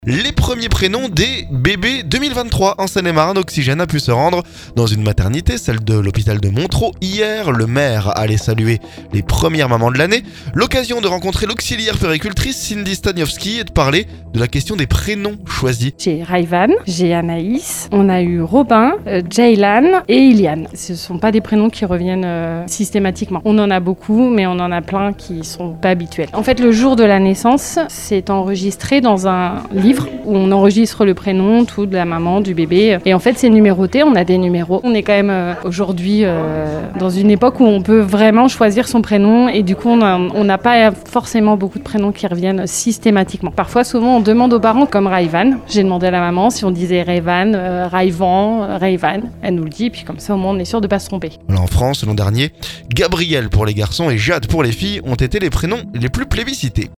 Les premiers prénoms des bébés 2023 en Seine-et-Marne. Oxygène a pu se rendre à la maternité de l'hôpital de Montereau lundi...